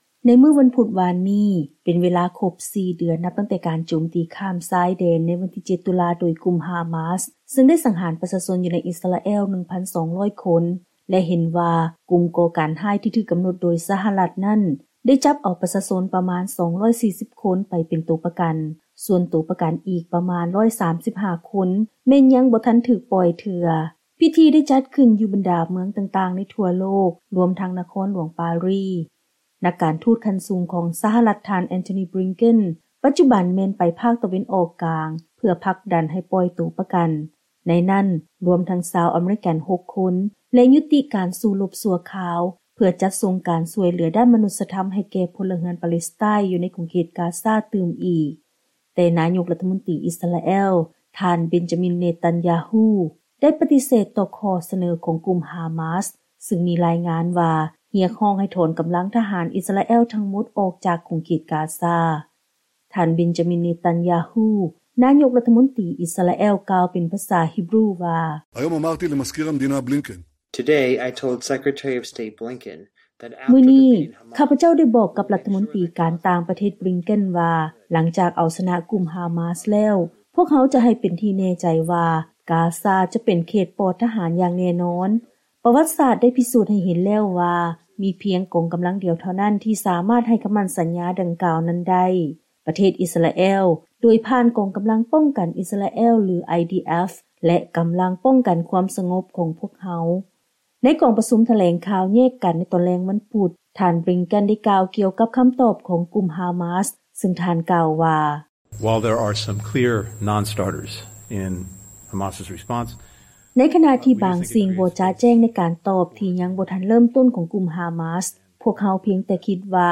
Benjamin Netanyahu, Israeli Prime Minister, in Hebrew with English voiceover.